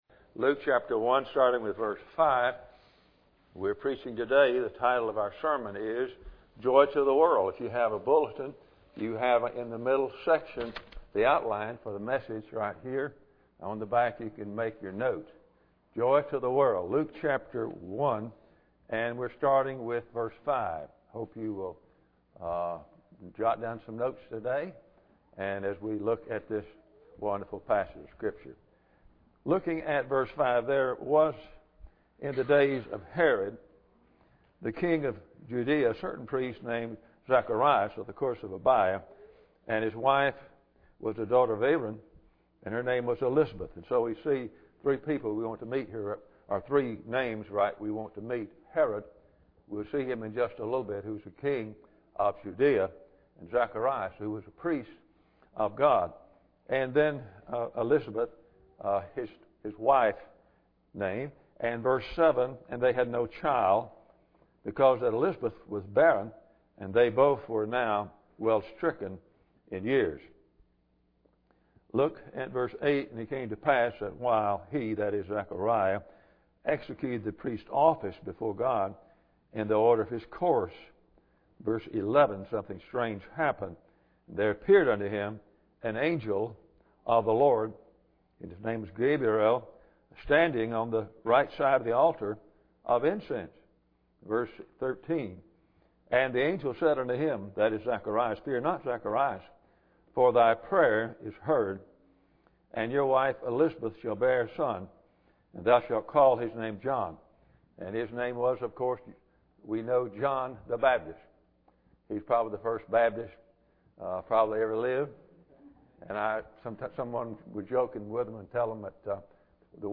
Luke 1:5-38 Service Type: Sunday Morning Bible Text